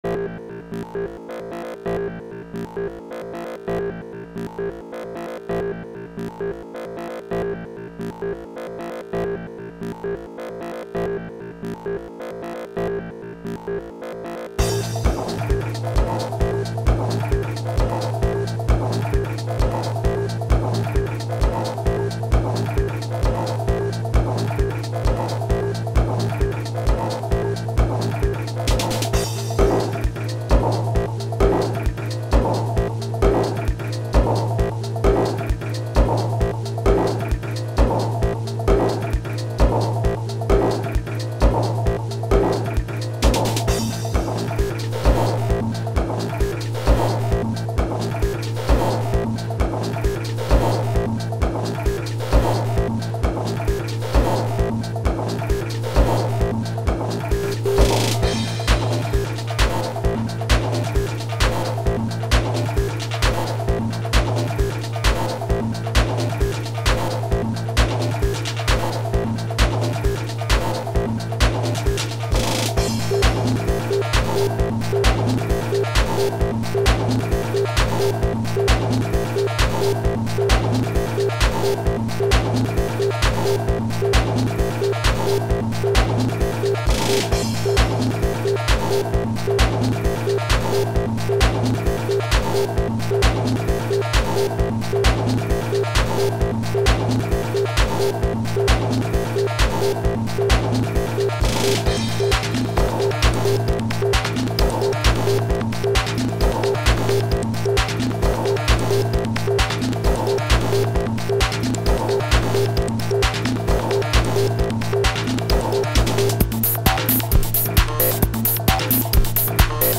Glitchy, dancey, melodic.
Glitched out funk-hop-dance